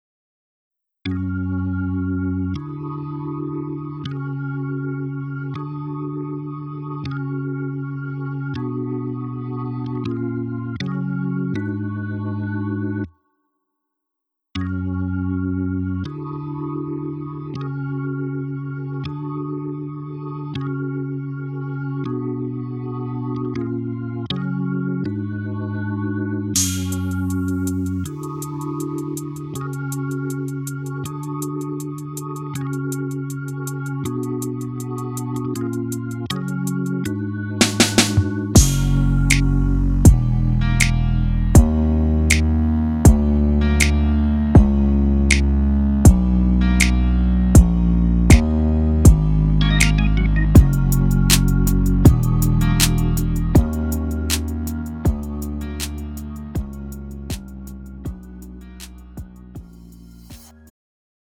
음정 원키 4:06
장르 가요 구분 Pro MR